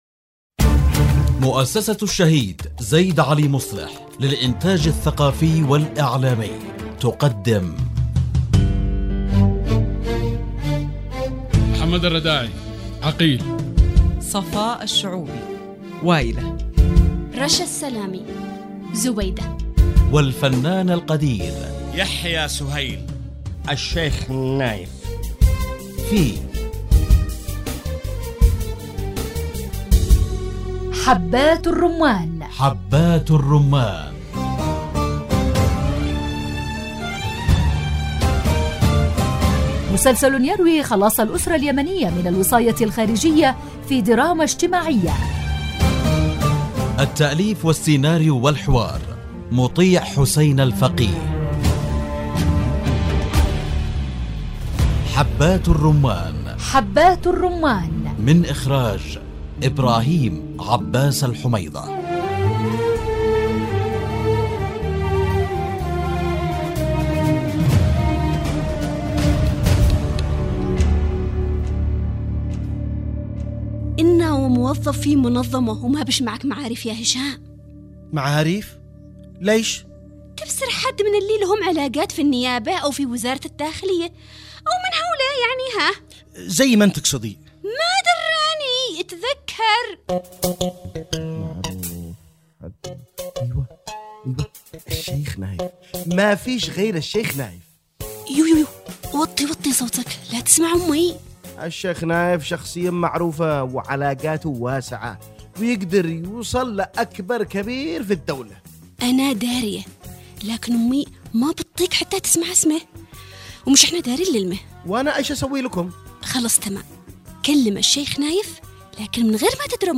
مسلسل يحكي خلاص الأسرة اليمنية من الوصاية الخارجية في دراما اجتماعية مع ألمع نجوم الشاشة اليمنية